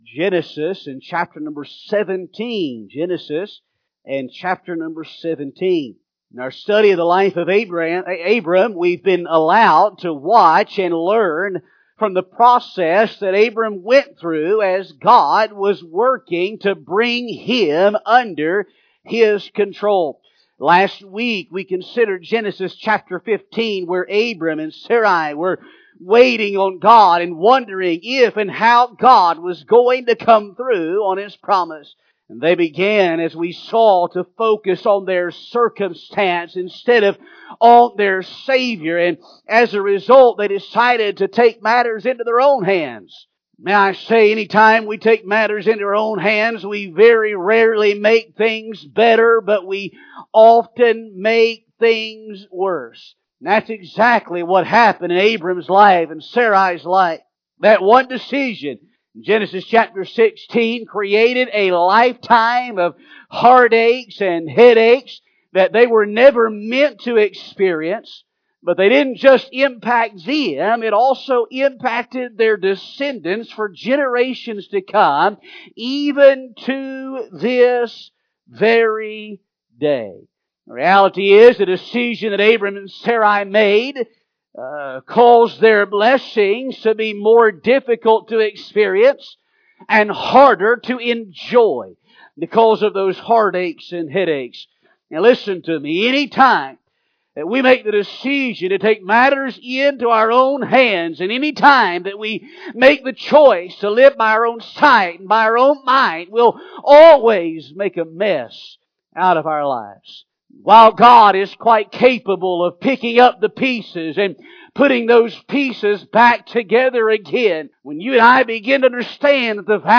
This is part 8 of the Under Control sermon series